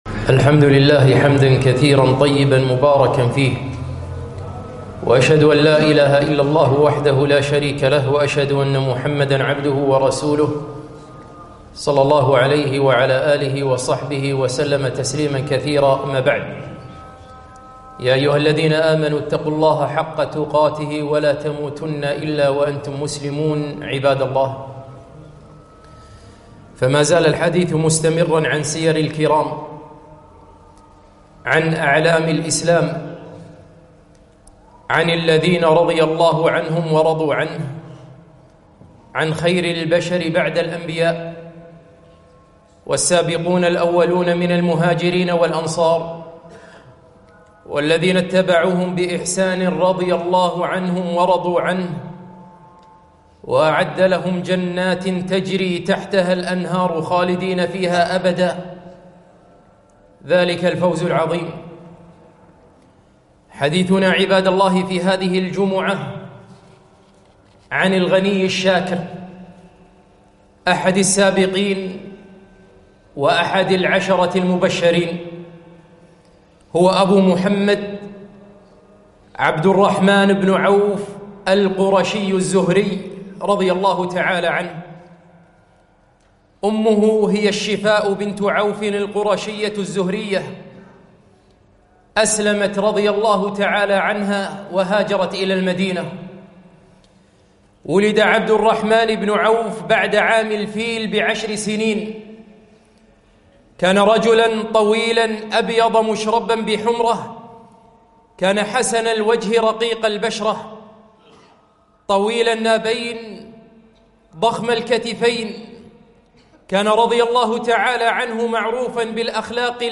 خطبة - عبد الرحمن بن عوف الغني الشاكر رضي الله عنه